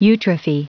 Prononciation du mot eutrophy en anglais (fichier audio)
Prononciation du mot : eutrophy